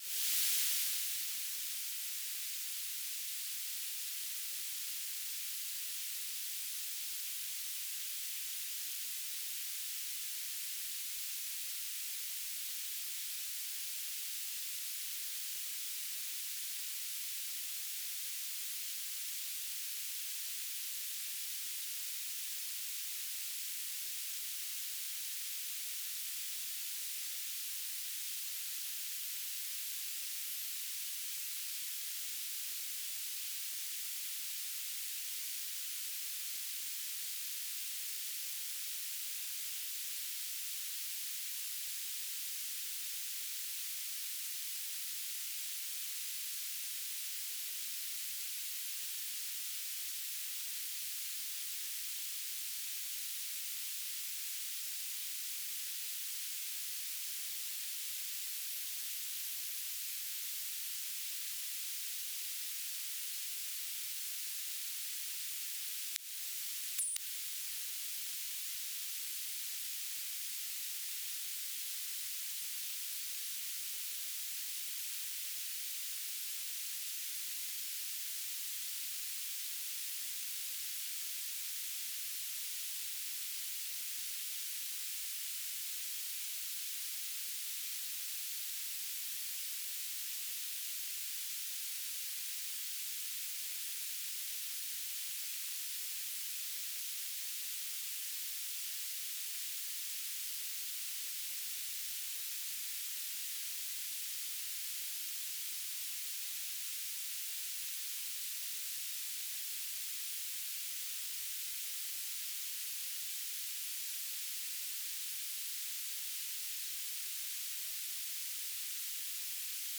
"transmitter_description": "BPSK1k2 AX.25 TLM",
"transmitter_mode": "BPSK",